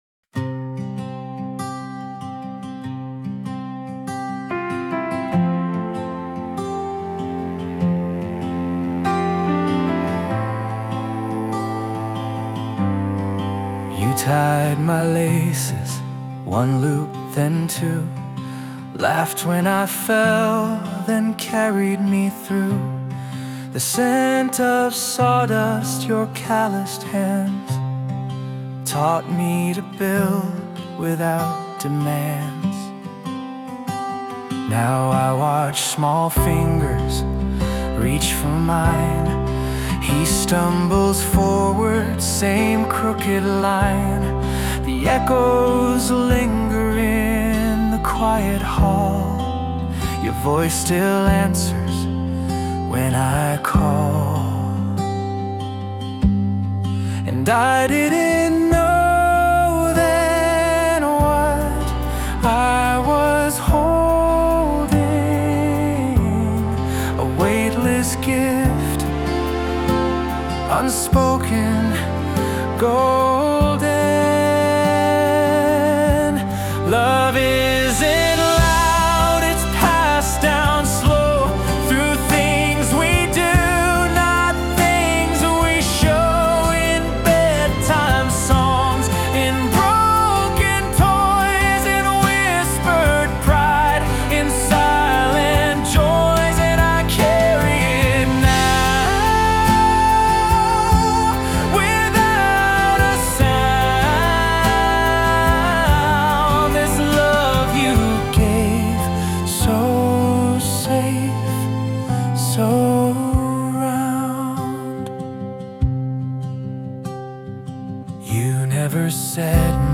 洋楽男性ボーカル著作権フリーBGM ボーカル
男性ボーカル洋楽洋楽 男性ボーカルプロフィールムービーお色直し退場お手紙朗読・花束贈呈ポップスバラード感動切ない
男性ボーカル（洋楽・英語）曲です。